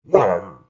COG_VO_grunt.ogg